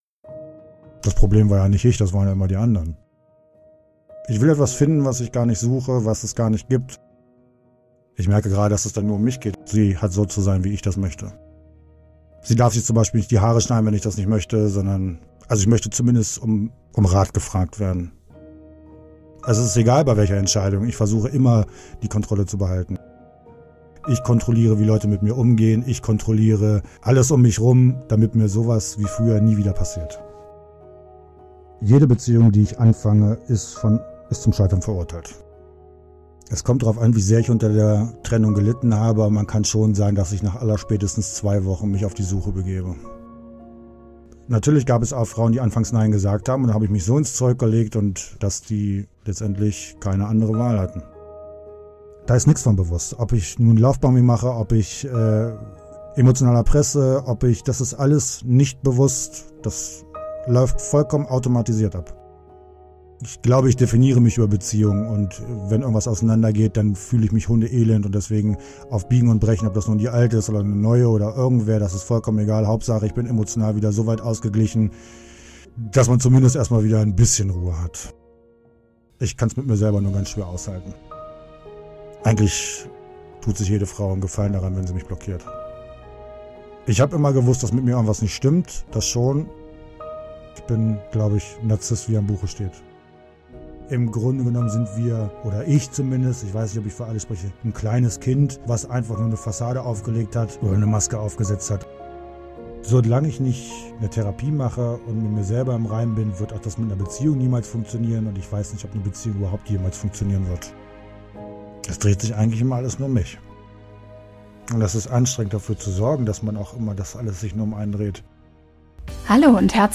Ein Gespräch über fehlende Empathie, das Unvermögen zu bereuen und unerfüllte Sehnsucht nach der großen Liebe – und warum er dennoch nicht aus den gescheiterten Beziehungen lernen kann.